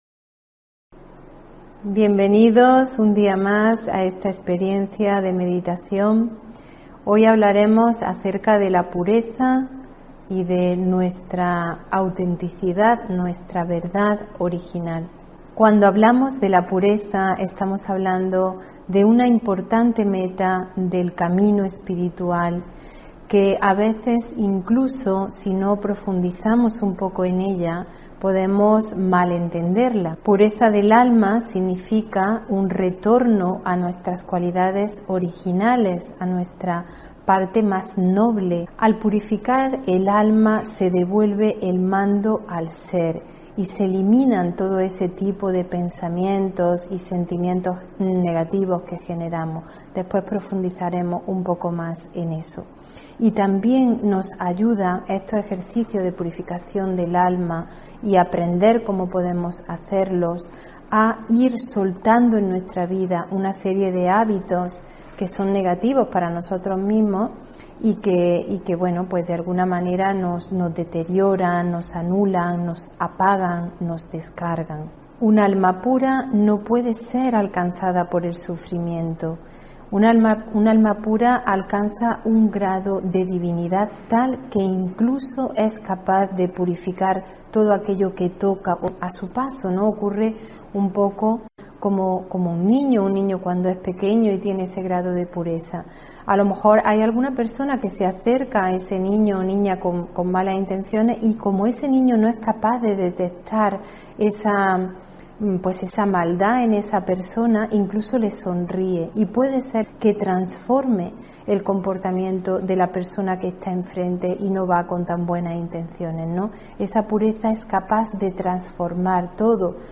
Meditación y conferencia: Activa tu energía vital (29 Julio 2022)